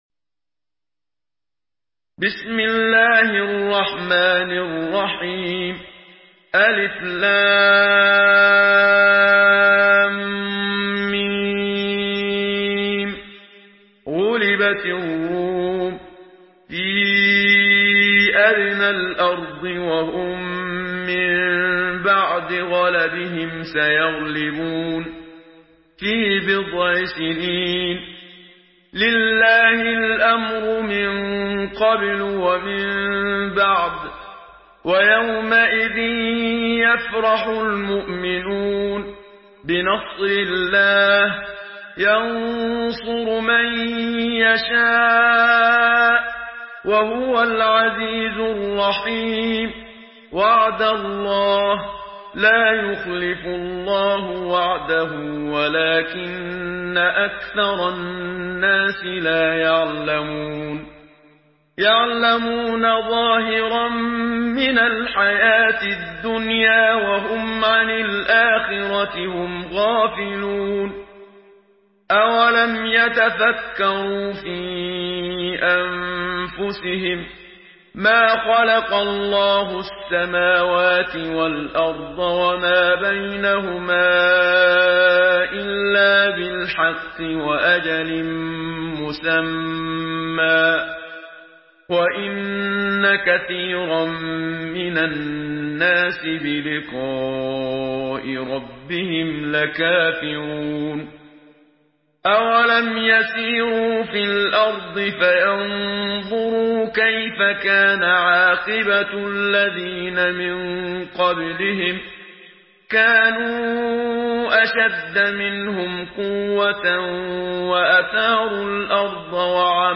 سورة الروم MP3 بصوت محمد صديق المنشاوي برواية حفص
مرتل حفص عن عاصم